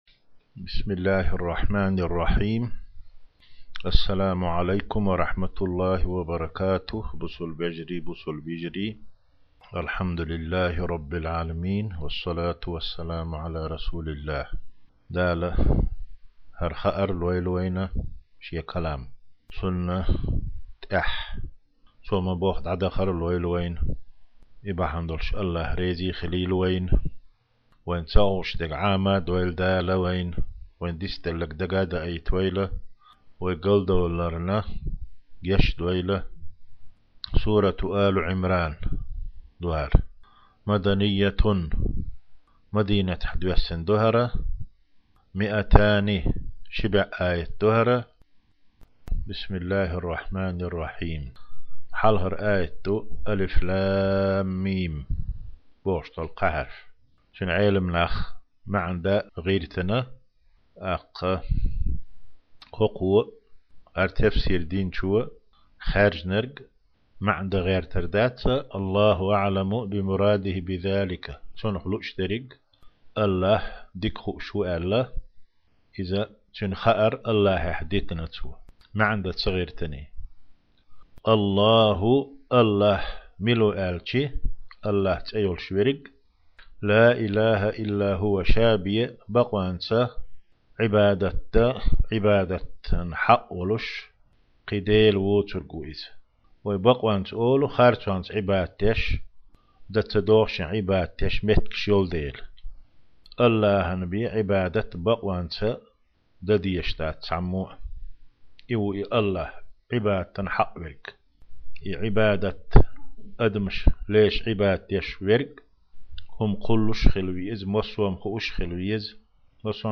1-ра дарс: Сурат Áли-Iимран 1-10 аят (Тафсирул-Жалалайн).